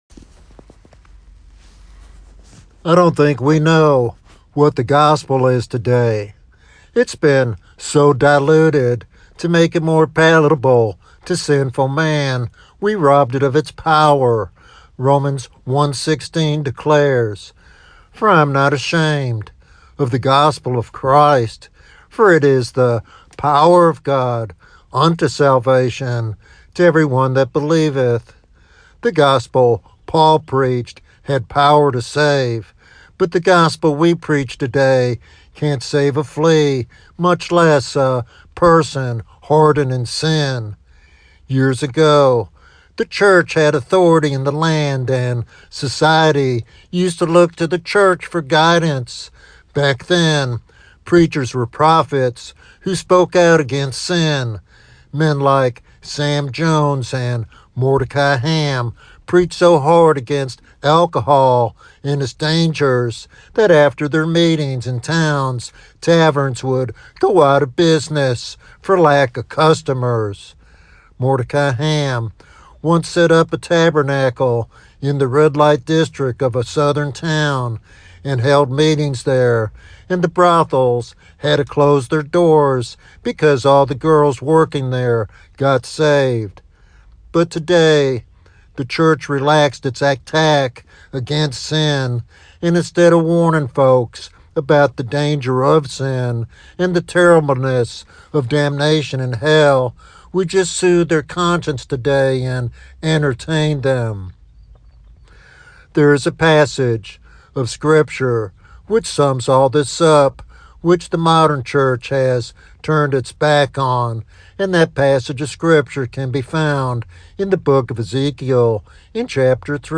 This sermon is a stirring call to preachers and believers alike to stand firm in truth and seek God's transformative grace for the nation.